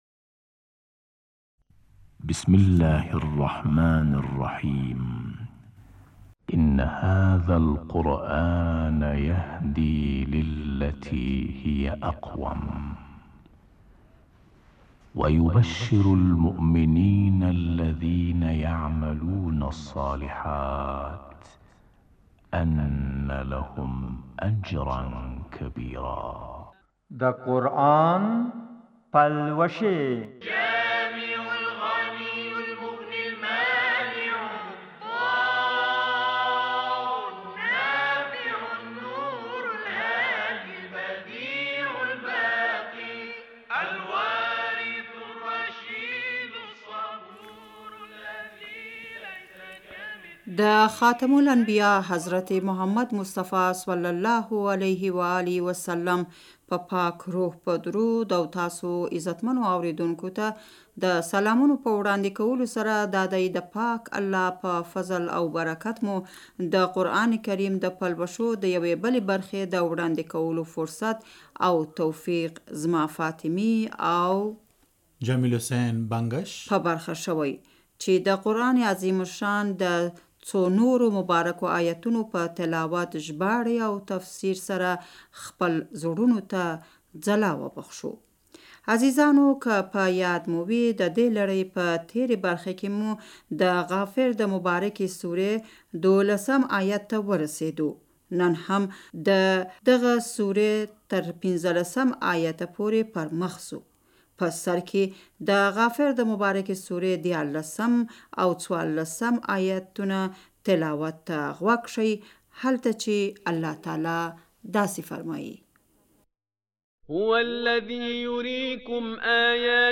د تهران د پښتو خپرونې خوږو مینه والو اوس هم درته د غافر سورې له دیارلسم تر څوارلسم پورې د آیتونو تلاؤت او ژباړه ستاسو خدمت ته وړاندې کوو.